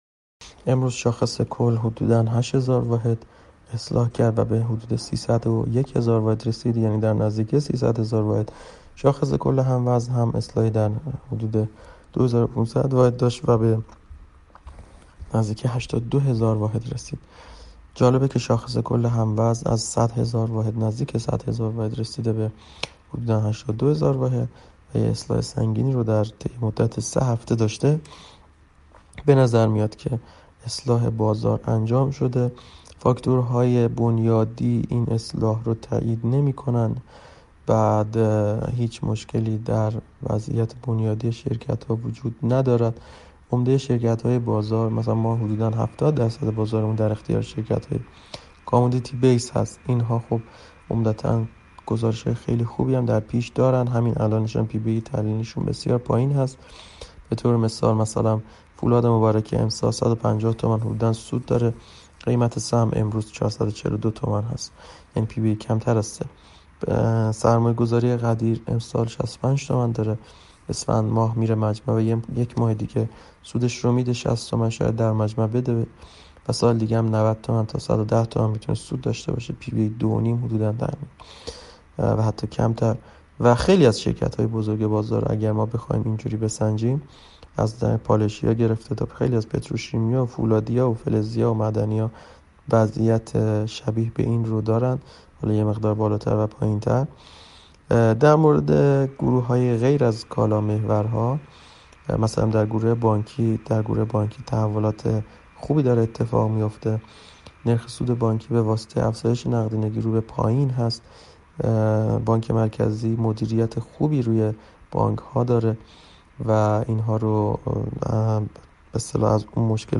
کارشناس بازارسرمایه؛